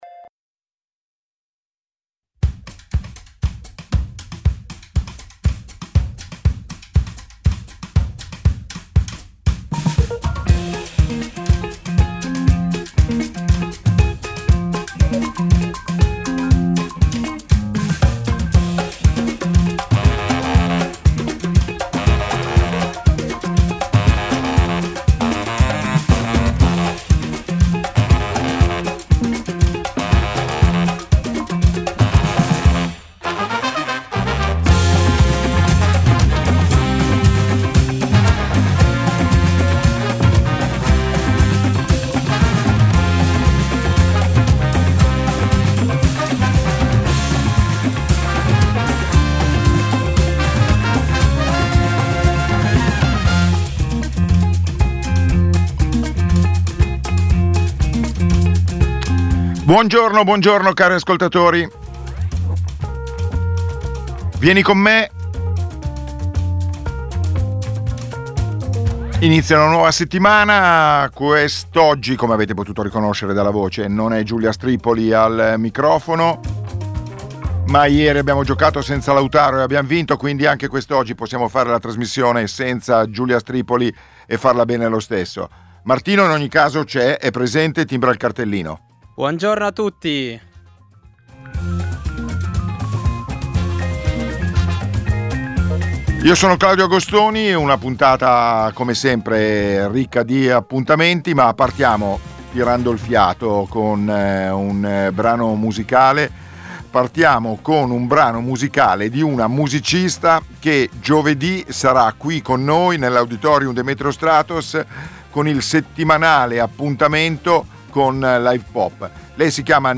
Uno spazio radiofonico per incontrarsi nella vita.